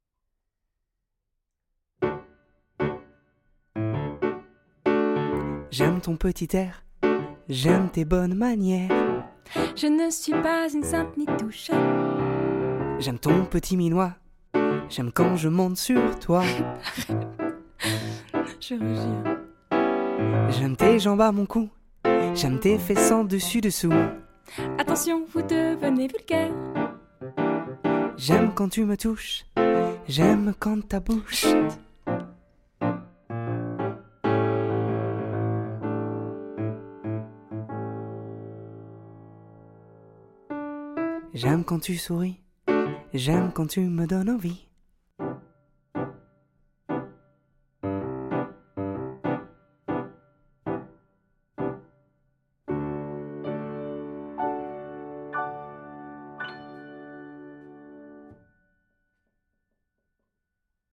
Comédien